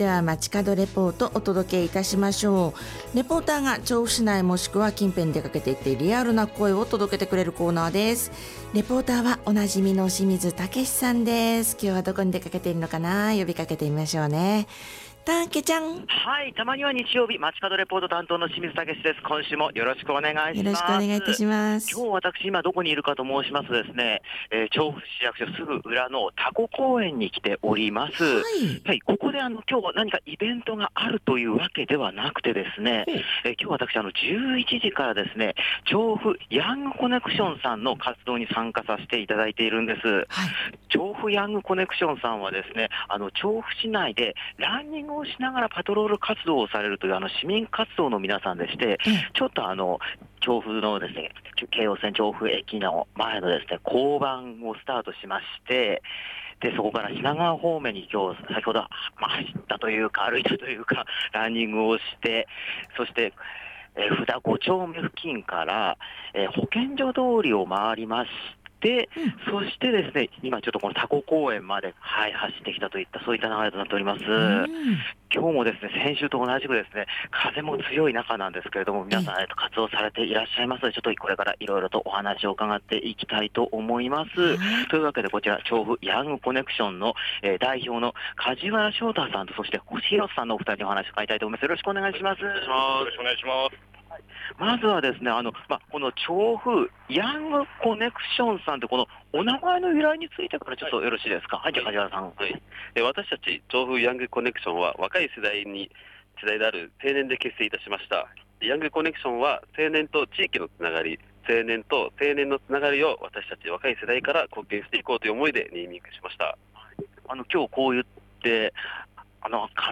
先週と打って変わって晴れた青空の下からお届けした本日の街角レポートは、